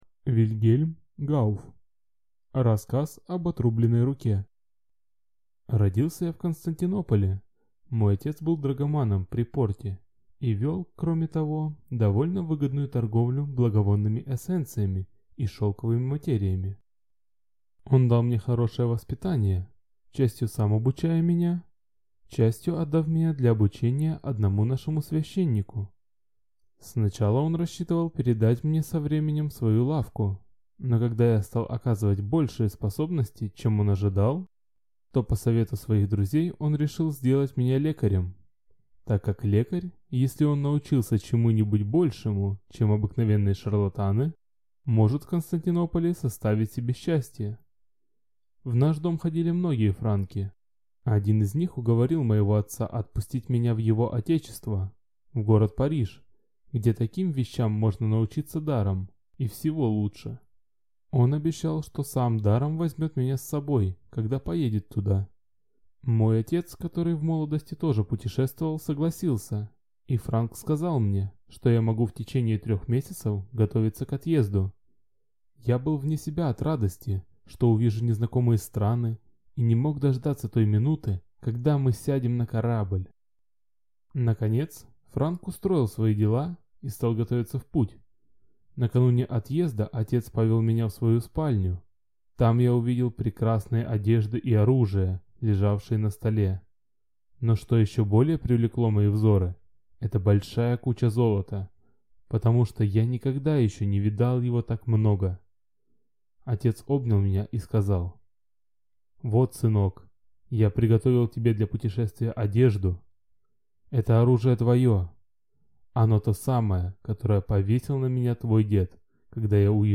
Аудиокнига Рассказ об отрубленной руке | Библиотека аудиокниг